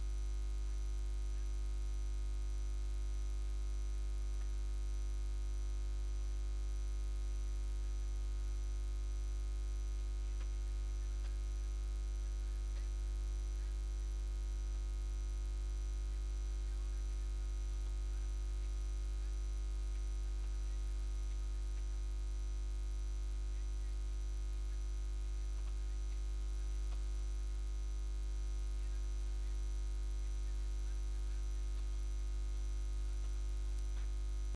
Durante el acto de entrega de los Premios Pablo Iglesias en la Caseta del PSOE en la Feria de Albacete
Cortes de audio de la rueda de prensa